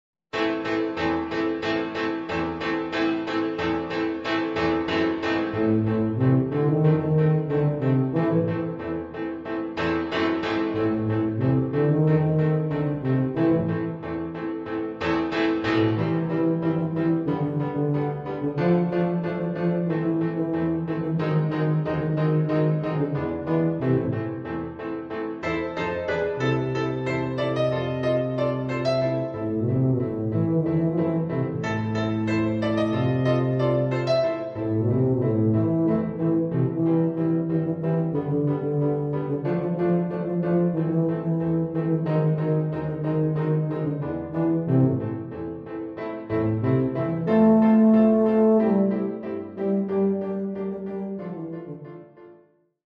A tried and tested series for brass.